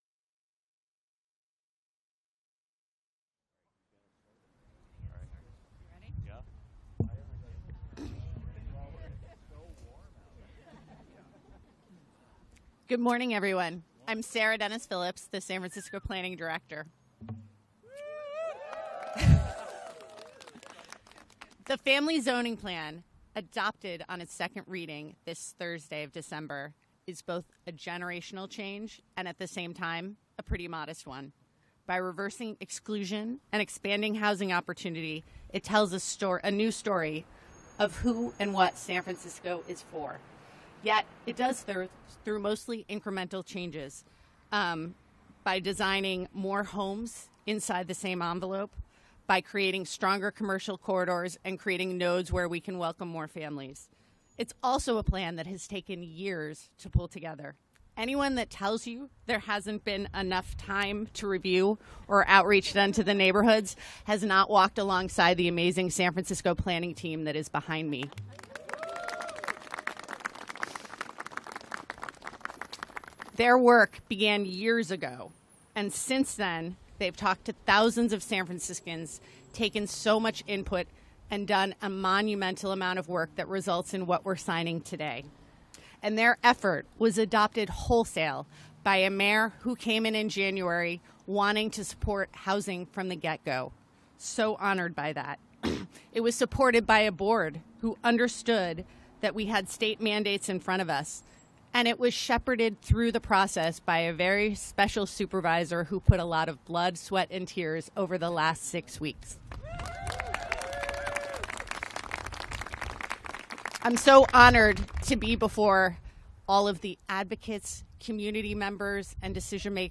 Mayor's Press Conference Audio Podcast